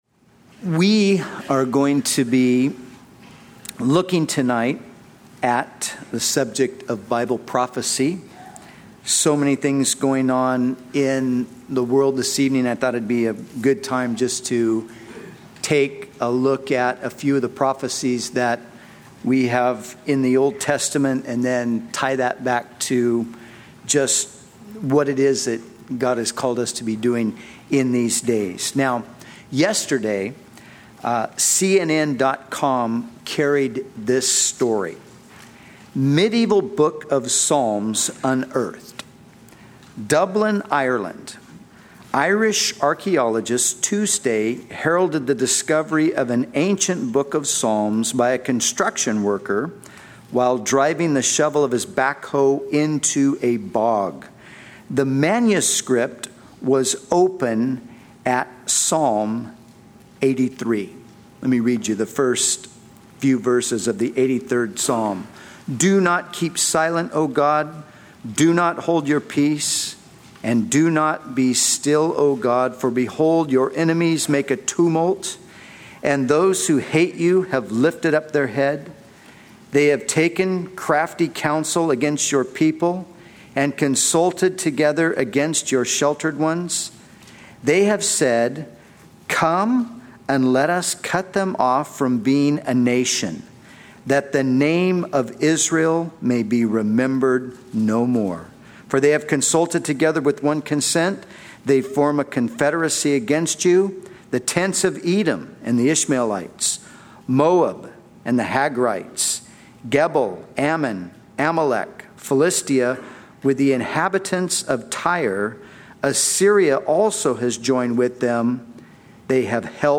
In this sermon, the preacher discusses the discovery of an ancient book of Psalms in Dublin, Ireland.